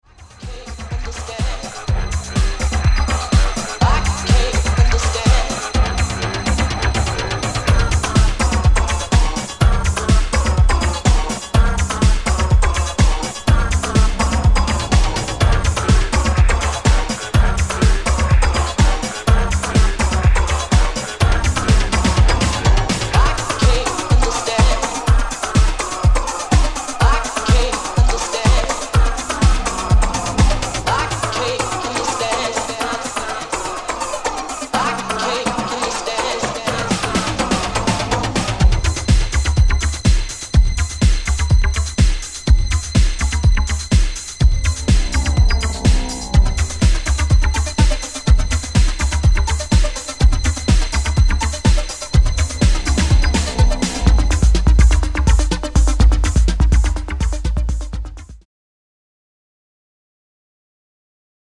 ＊視聴音源は実物のレコードから録音してます。